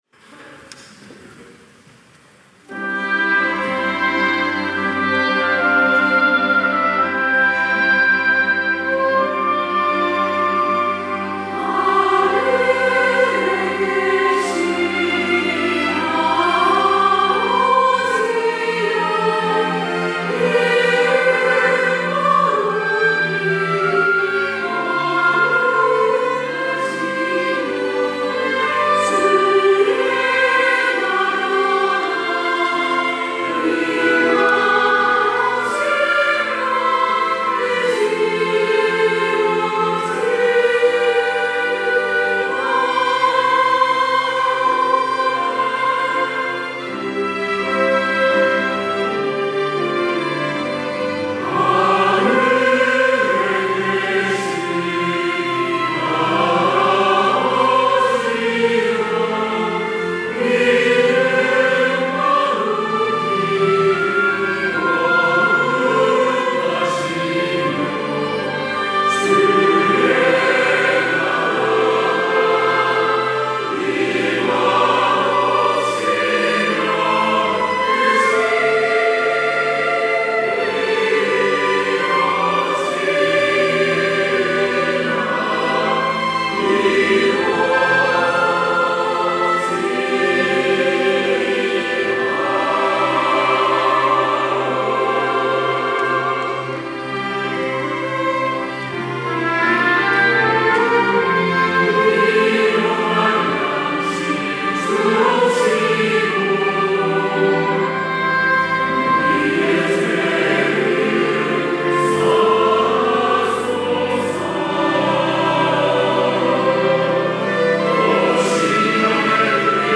호산나(주일3부) - 주 기도
찬양대